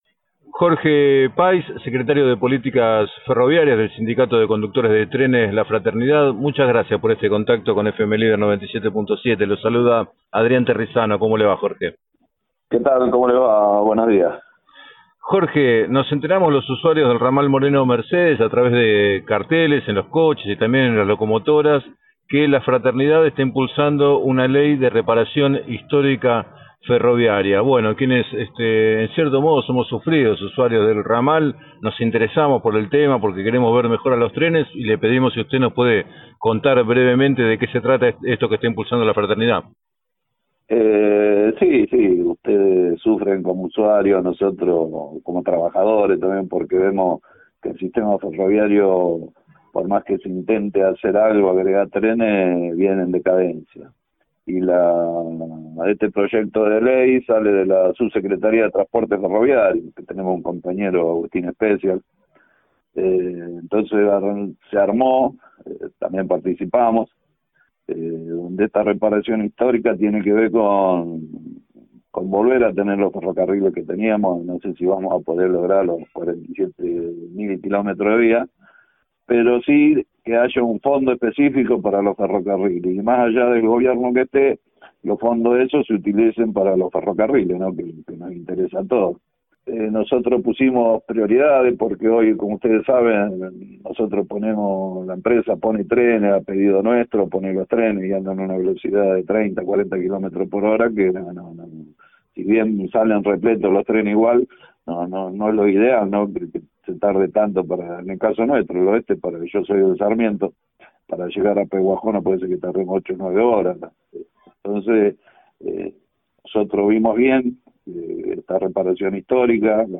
En declaraciones al programa 7 a 9 de FM Líder 97.7